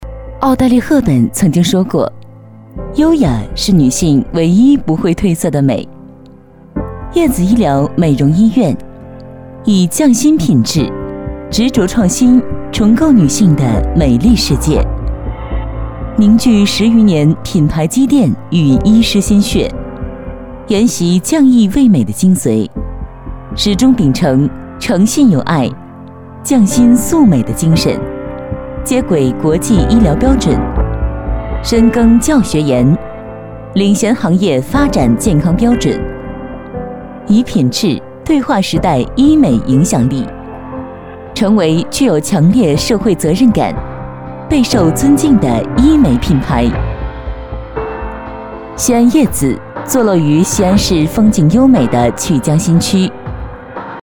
成熟知性 企业宣传配音
成熟知性，大气女音，擅长政府报告类，独白类、新闻专题、宣传片题材。